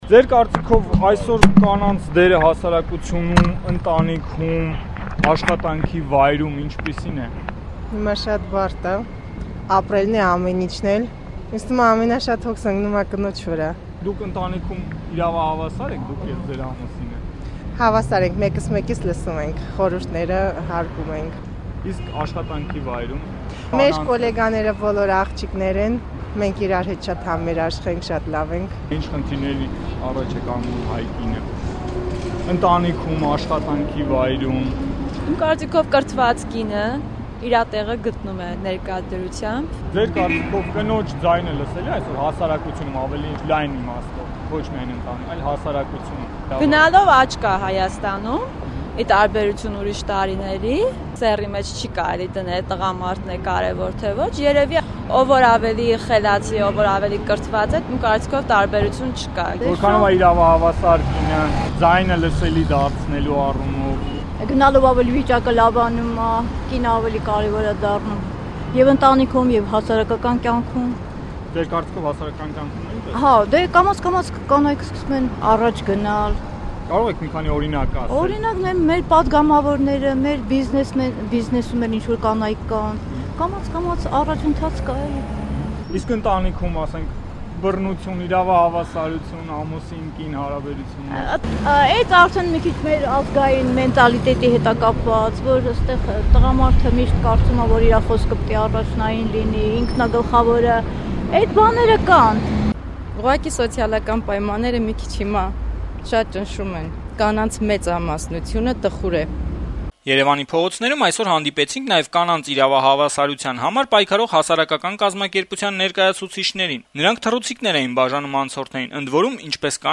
«Ազատություն» ռադիոկայանի հետ զրույցներում երեւանցի կանայք տարբեր` հաճախ տրամագծորեն հակառակ կարծիքներ բարձրաձայնեցին հասարակության մեջ կանանց զբաղեցրած տեղի ու կատարած դերի մասին: